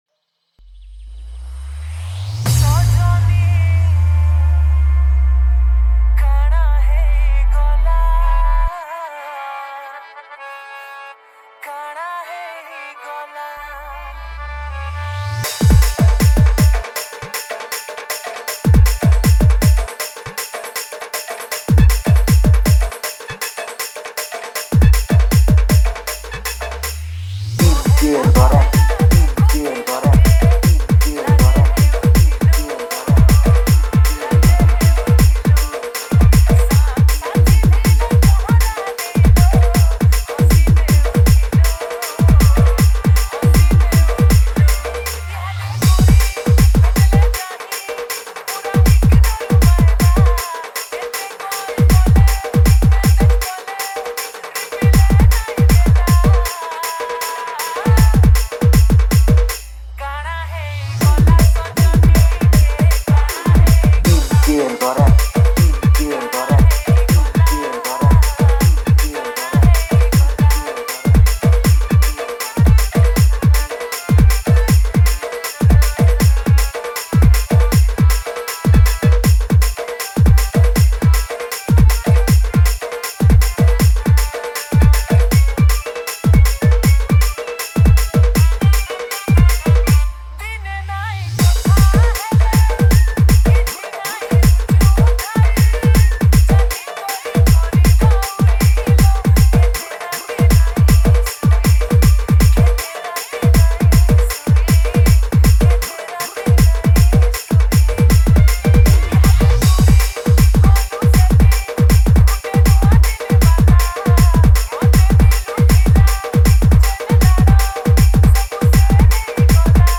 • Category: New Sambalpuri DJ Song 2025